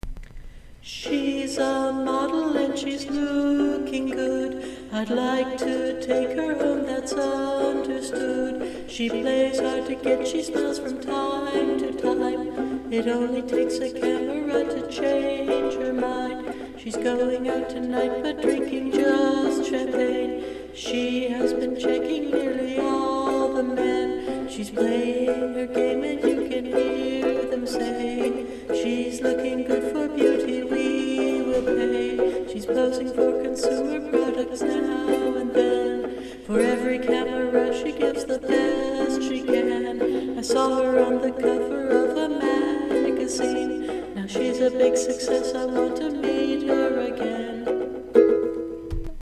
And then I thought my ukulele might get jealous
is eerie!